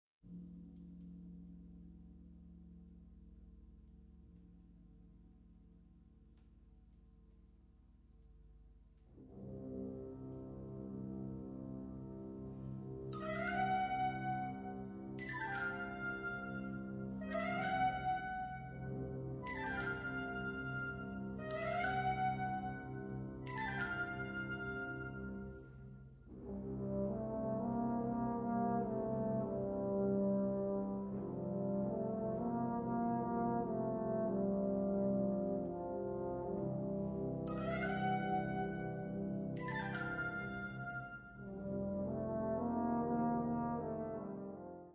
Instrumentation Ha (orchestre d'harmonie)
des mélodies larges et lentes et une harmonie dense